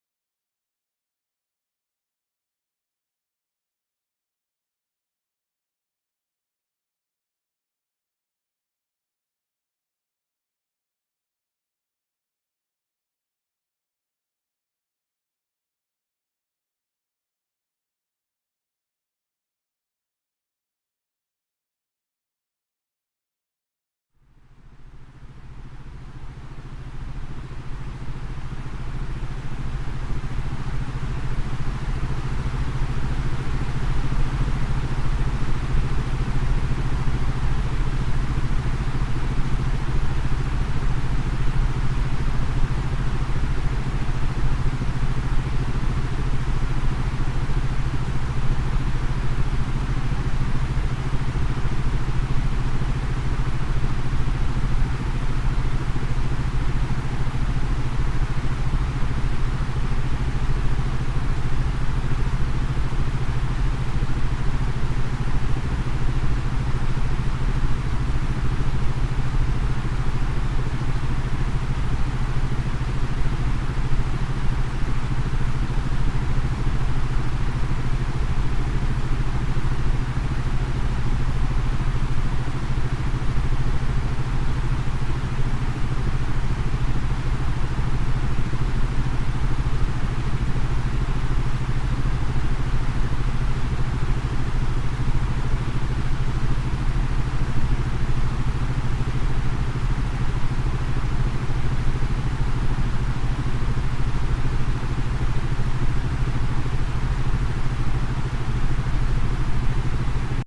Sie erhalten mit dem EINSCHLAFMEISTER eine Mischung von Sounds vorwiegend aus dem Bereich der Isochronen Töne und einem geringen Anteil an Binauralen Beats mit unterschiedlichem Wirkungsziel.
Hörprobe  "Schlafbalsam-Rauschen-Platin" >>